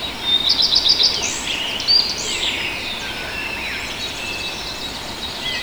• early mountain birds habitat 4.wav
Great place to record the natural habitat of birds and animals in the beautiful Southern Carpathian Mountains. Recorded with Tascam DR 40